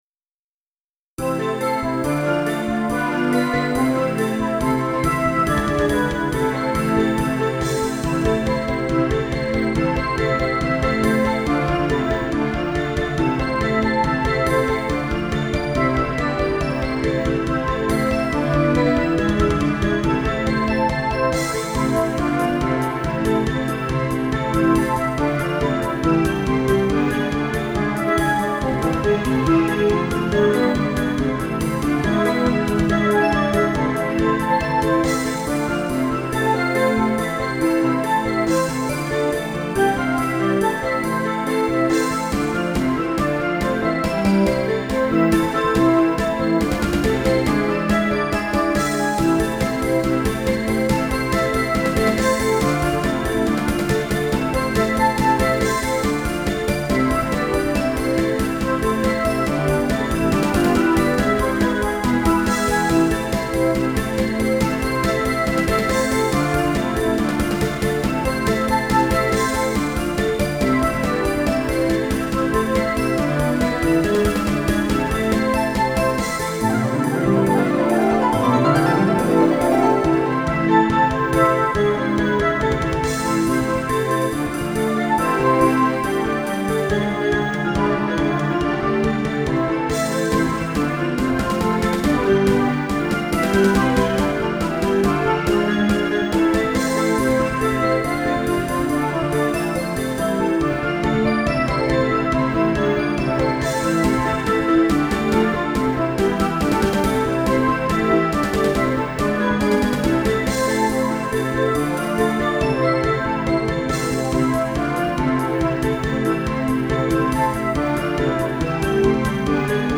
〜オフボーカル版〜